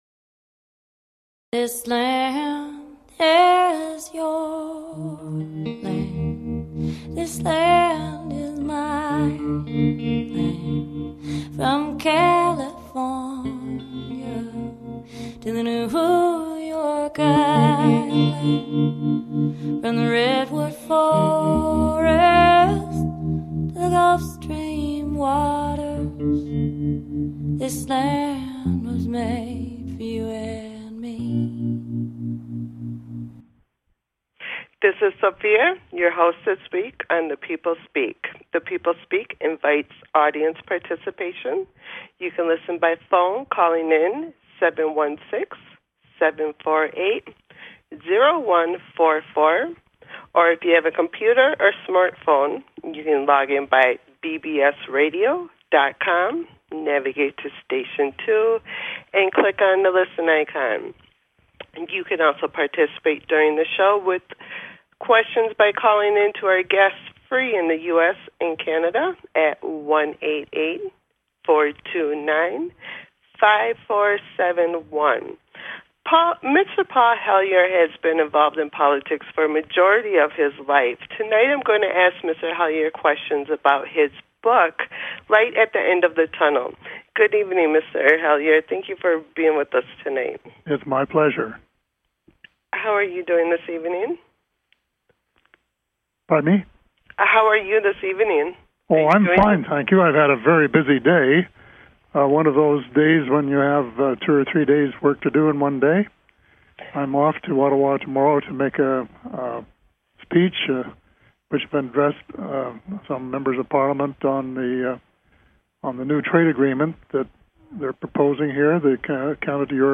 Guest, Paul Hellyer